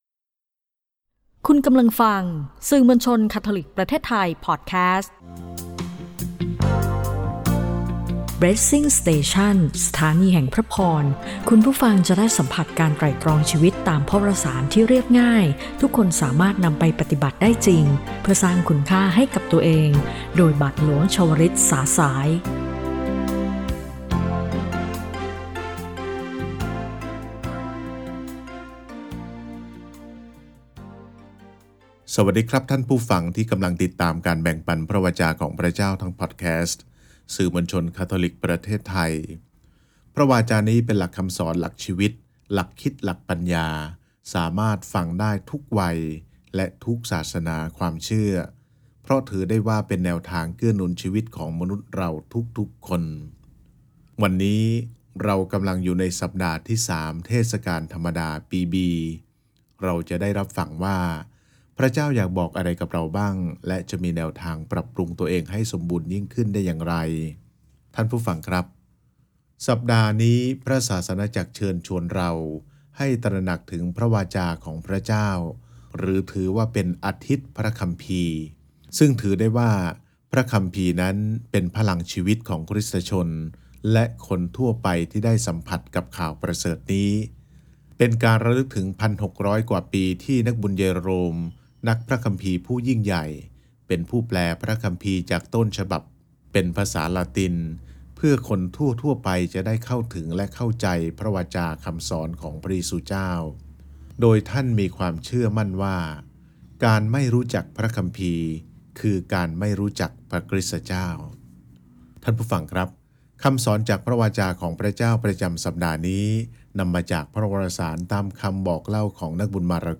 - รายการวิทยุคาทอลิก